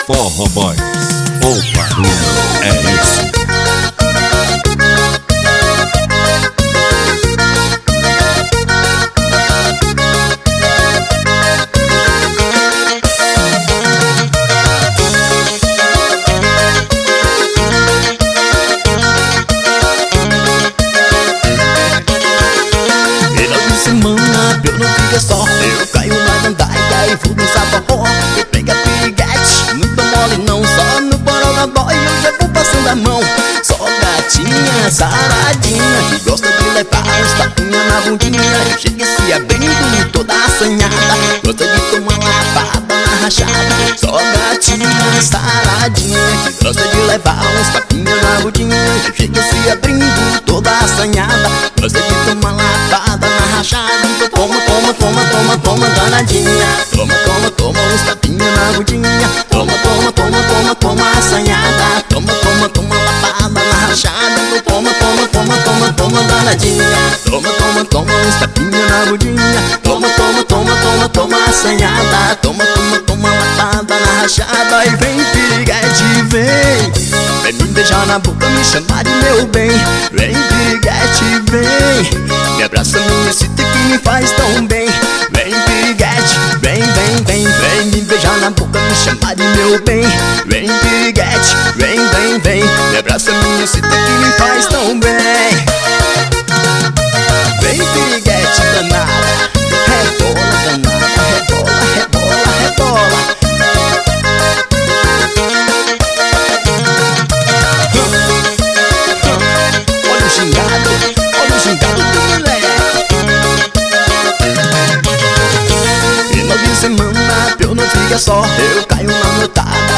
2024-05-25 14:32:55 Gênero: Forró Views